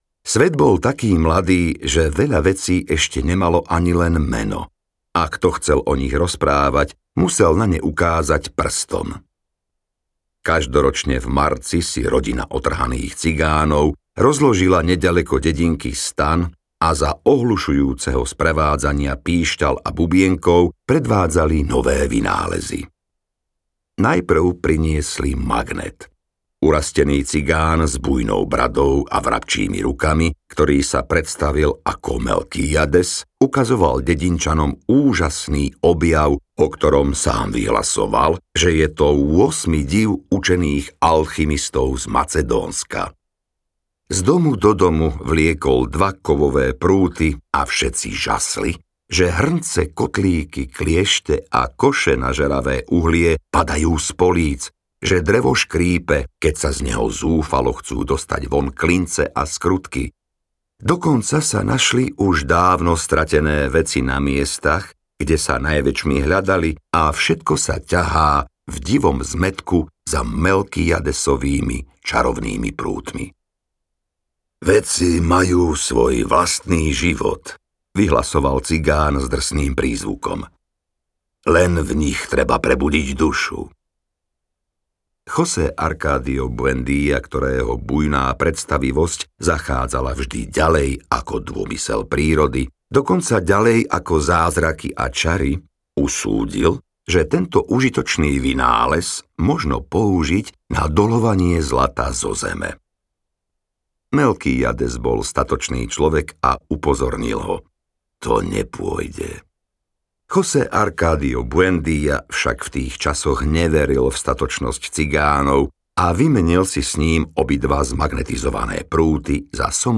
Sto rokov samoty audiokniha
Ukázka z knihy